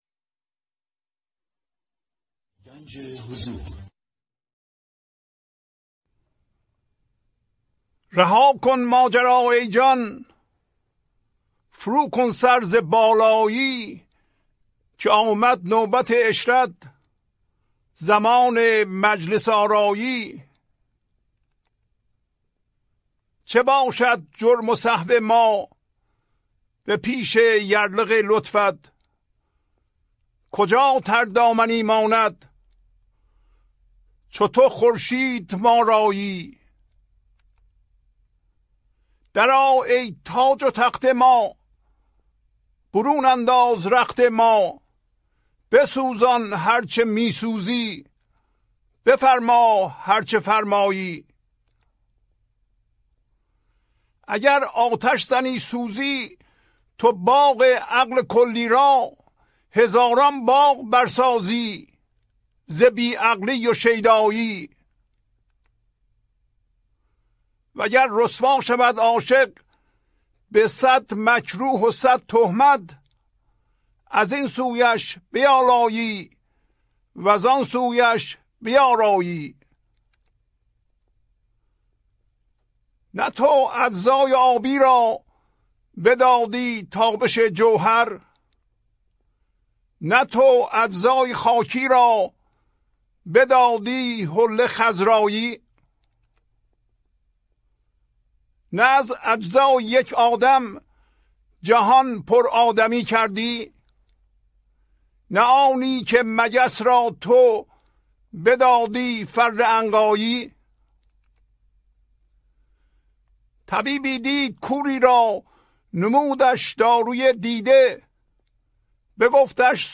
خوانش تمام ابیات این برنامه - فایل صوتی
1053-Poems-Voice.mp3